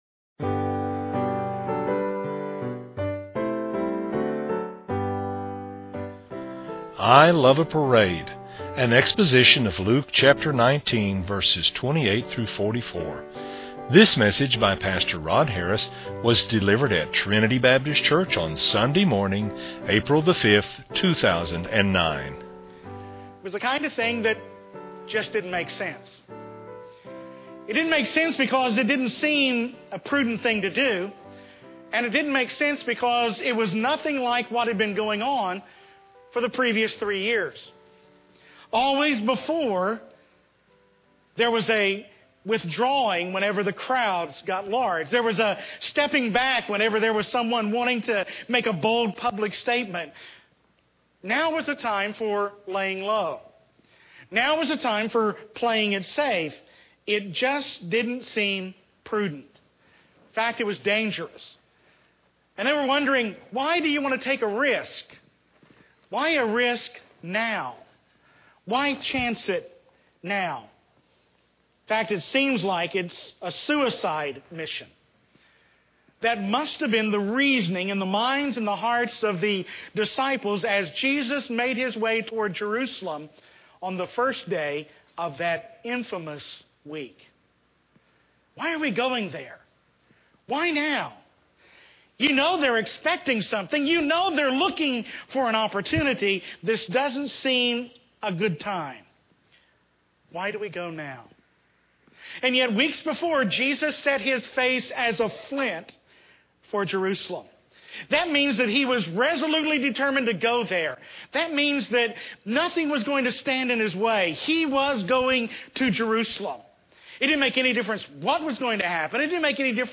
delivered a Trinity Baptist Church on Sunday morning